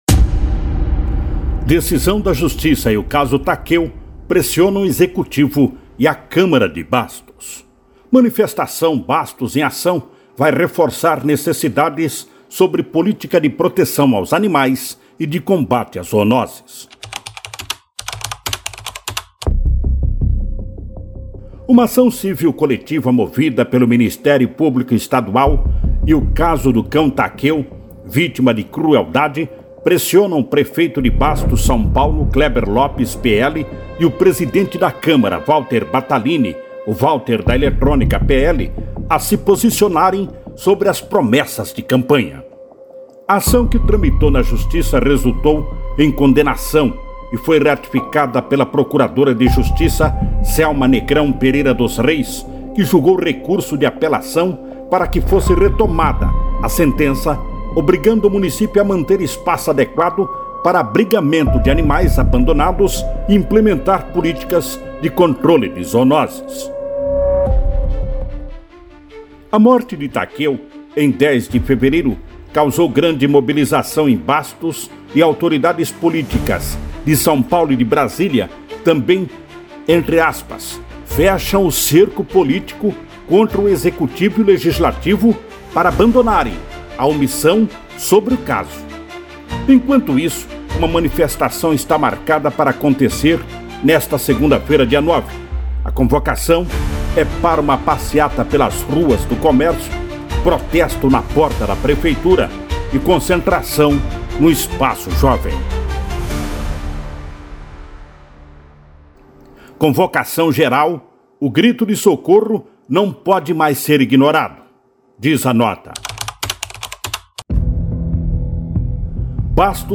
Manifestação “Bastos em Ação” vai reforçar necessidades sobre política de proteção aos animais e de combate às zoonoses – ouça matéria